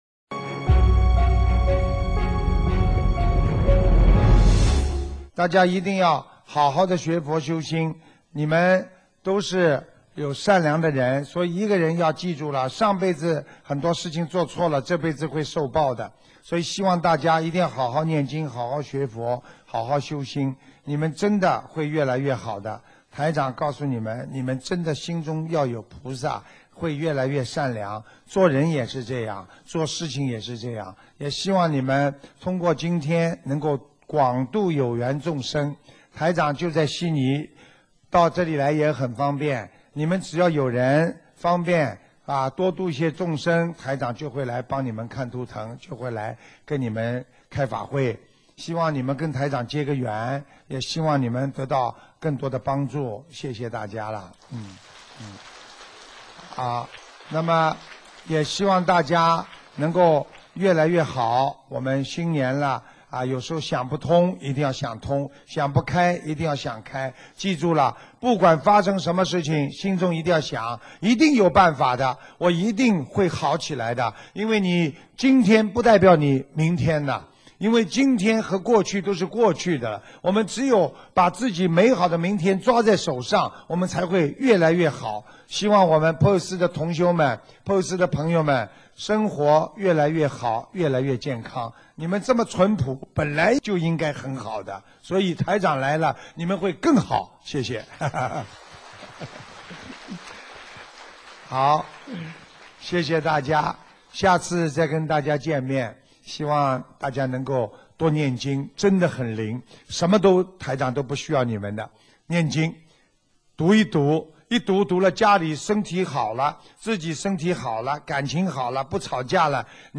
2015年3月8日澳大利亚珀斯玄艺综述大型解答会结束语-经典感人开示节选